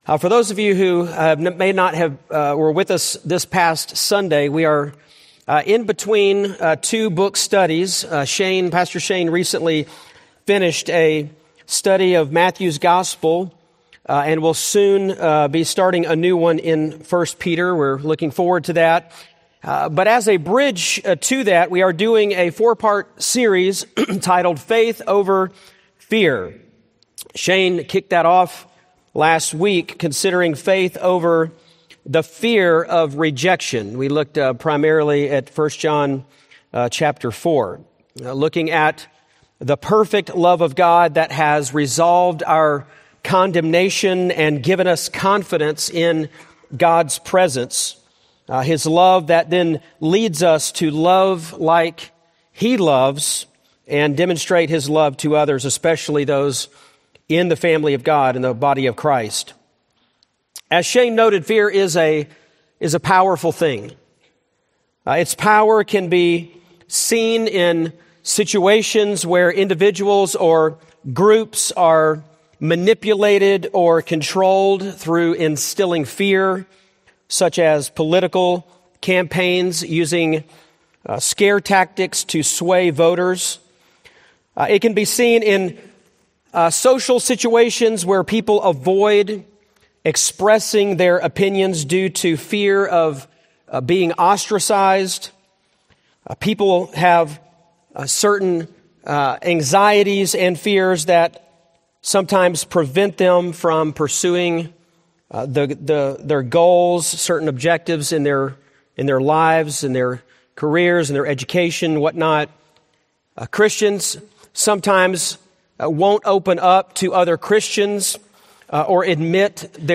Series: Faith Over Fear, Sunday Sermons